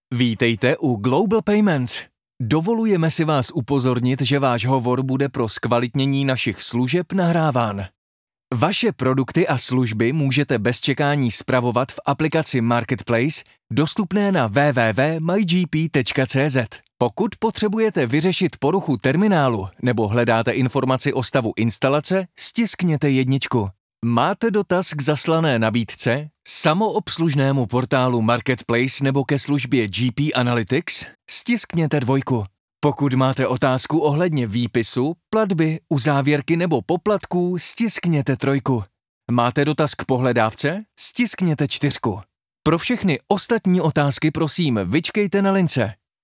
Obdržíte kvalitní zvukovou stopu - voiceover (wav/mp3), vyčištěnou od nádechů a rušivých zvuků, nachystanou pro synchronizaci s vaším videem.
Mužský voiceover do Vašeho videa (Voiceover / 90 sekund)